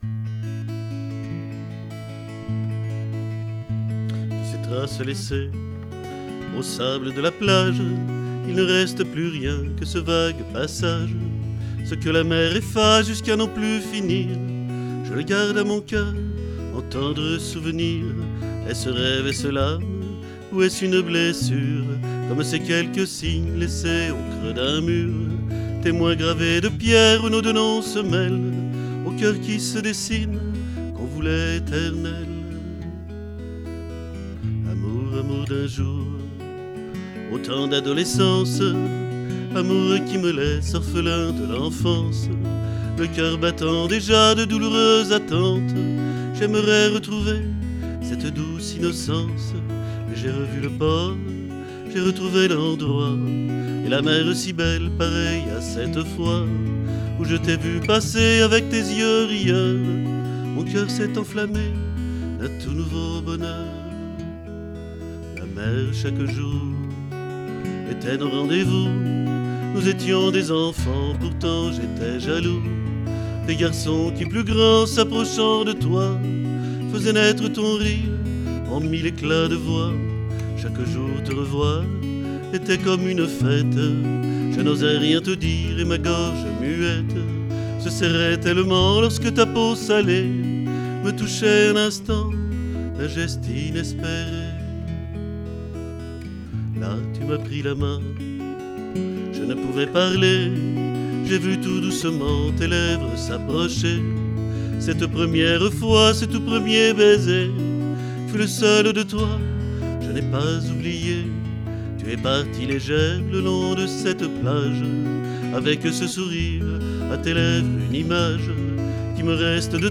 Texte, musique, chant, guitare